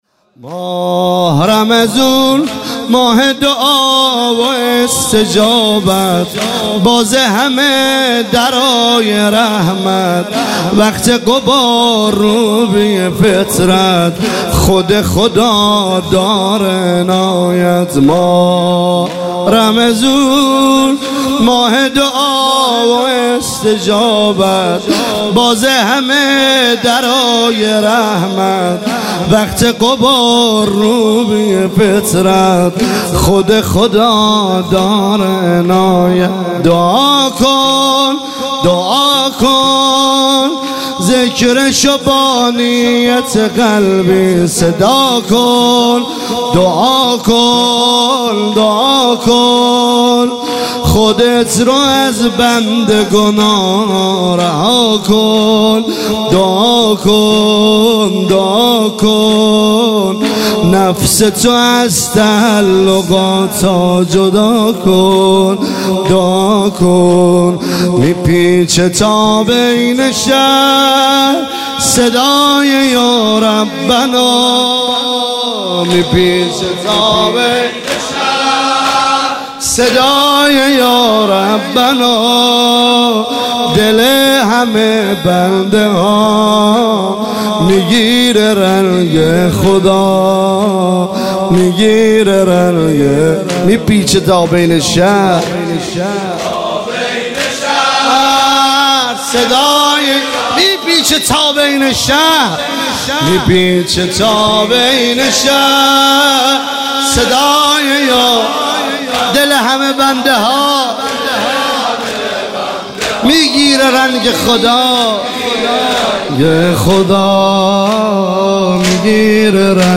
برگزار کننده هیئت حسین جان علیه السلام گرگان